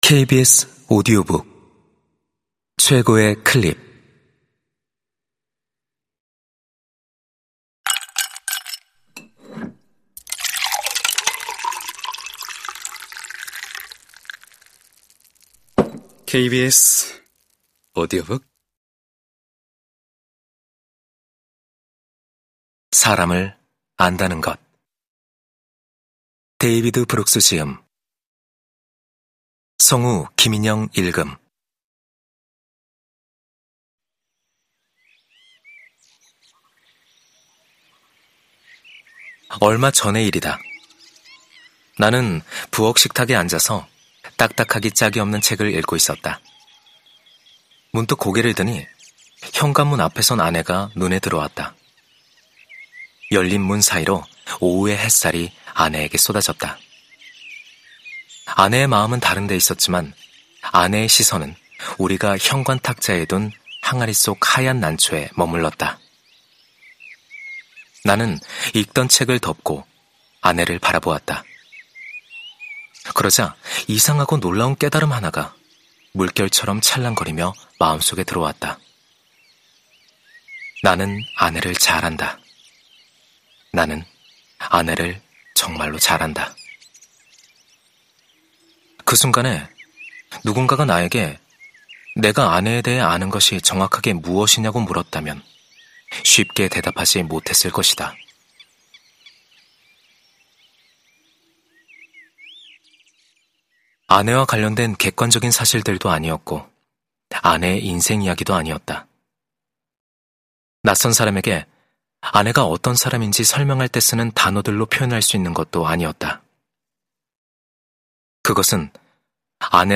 KBS 오디오북 - 최고의 클립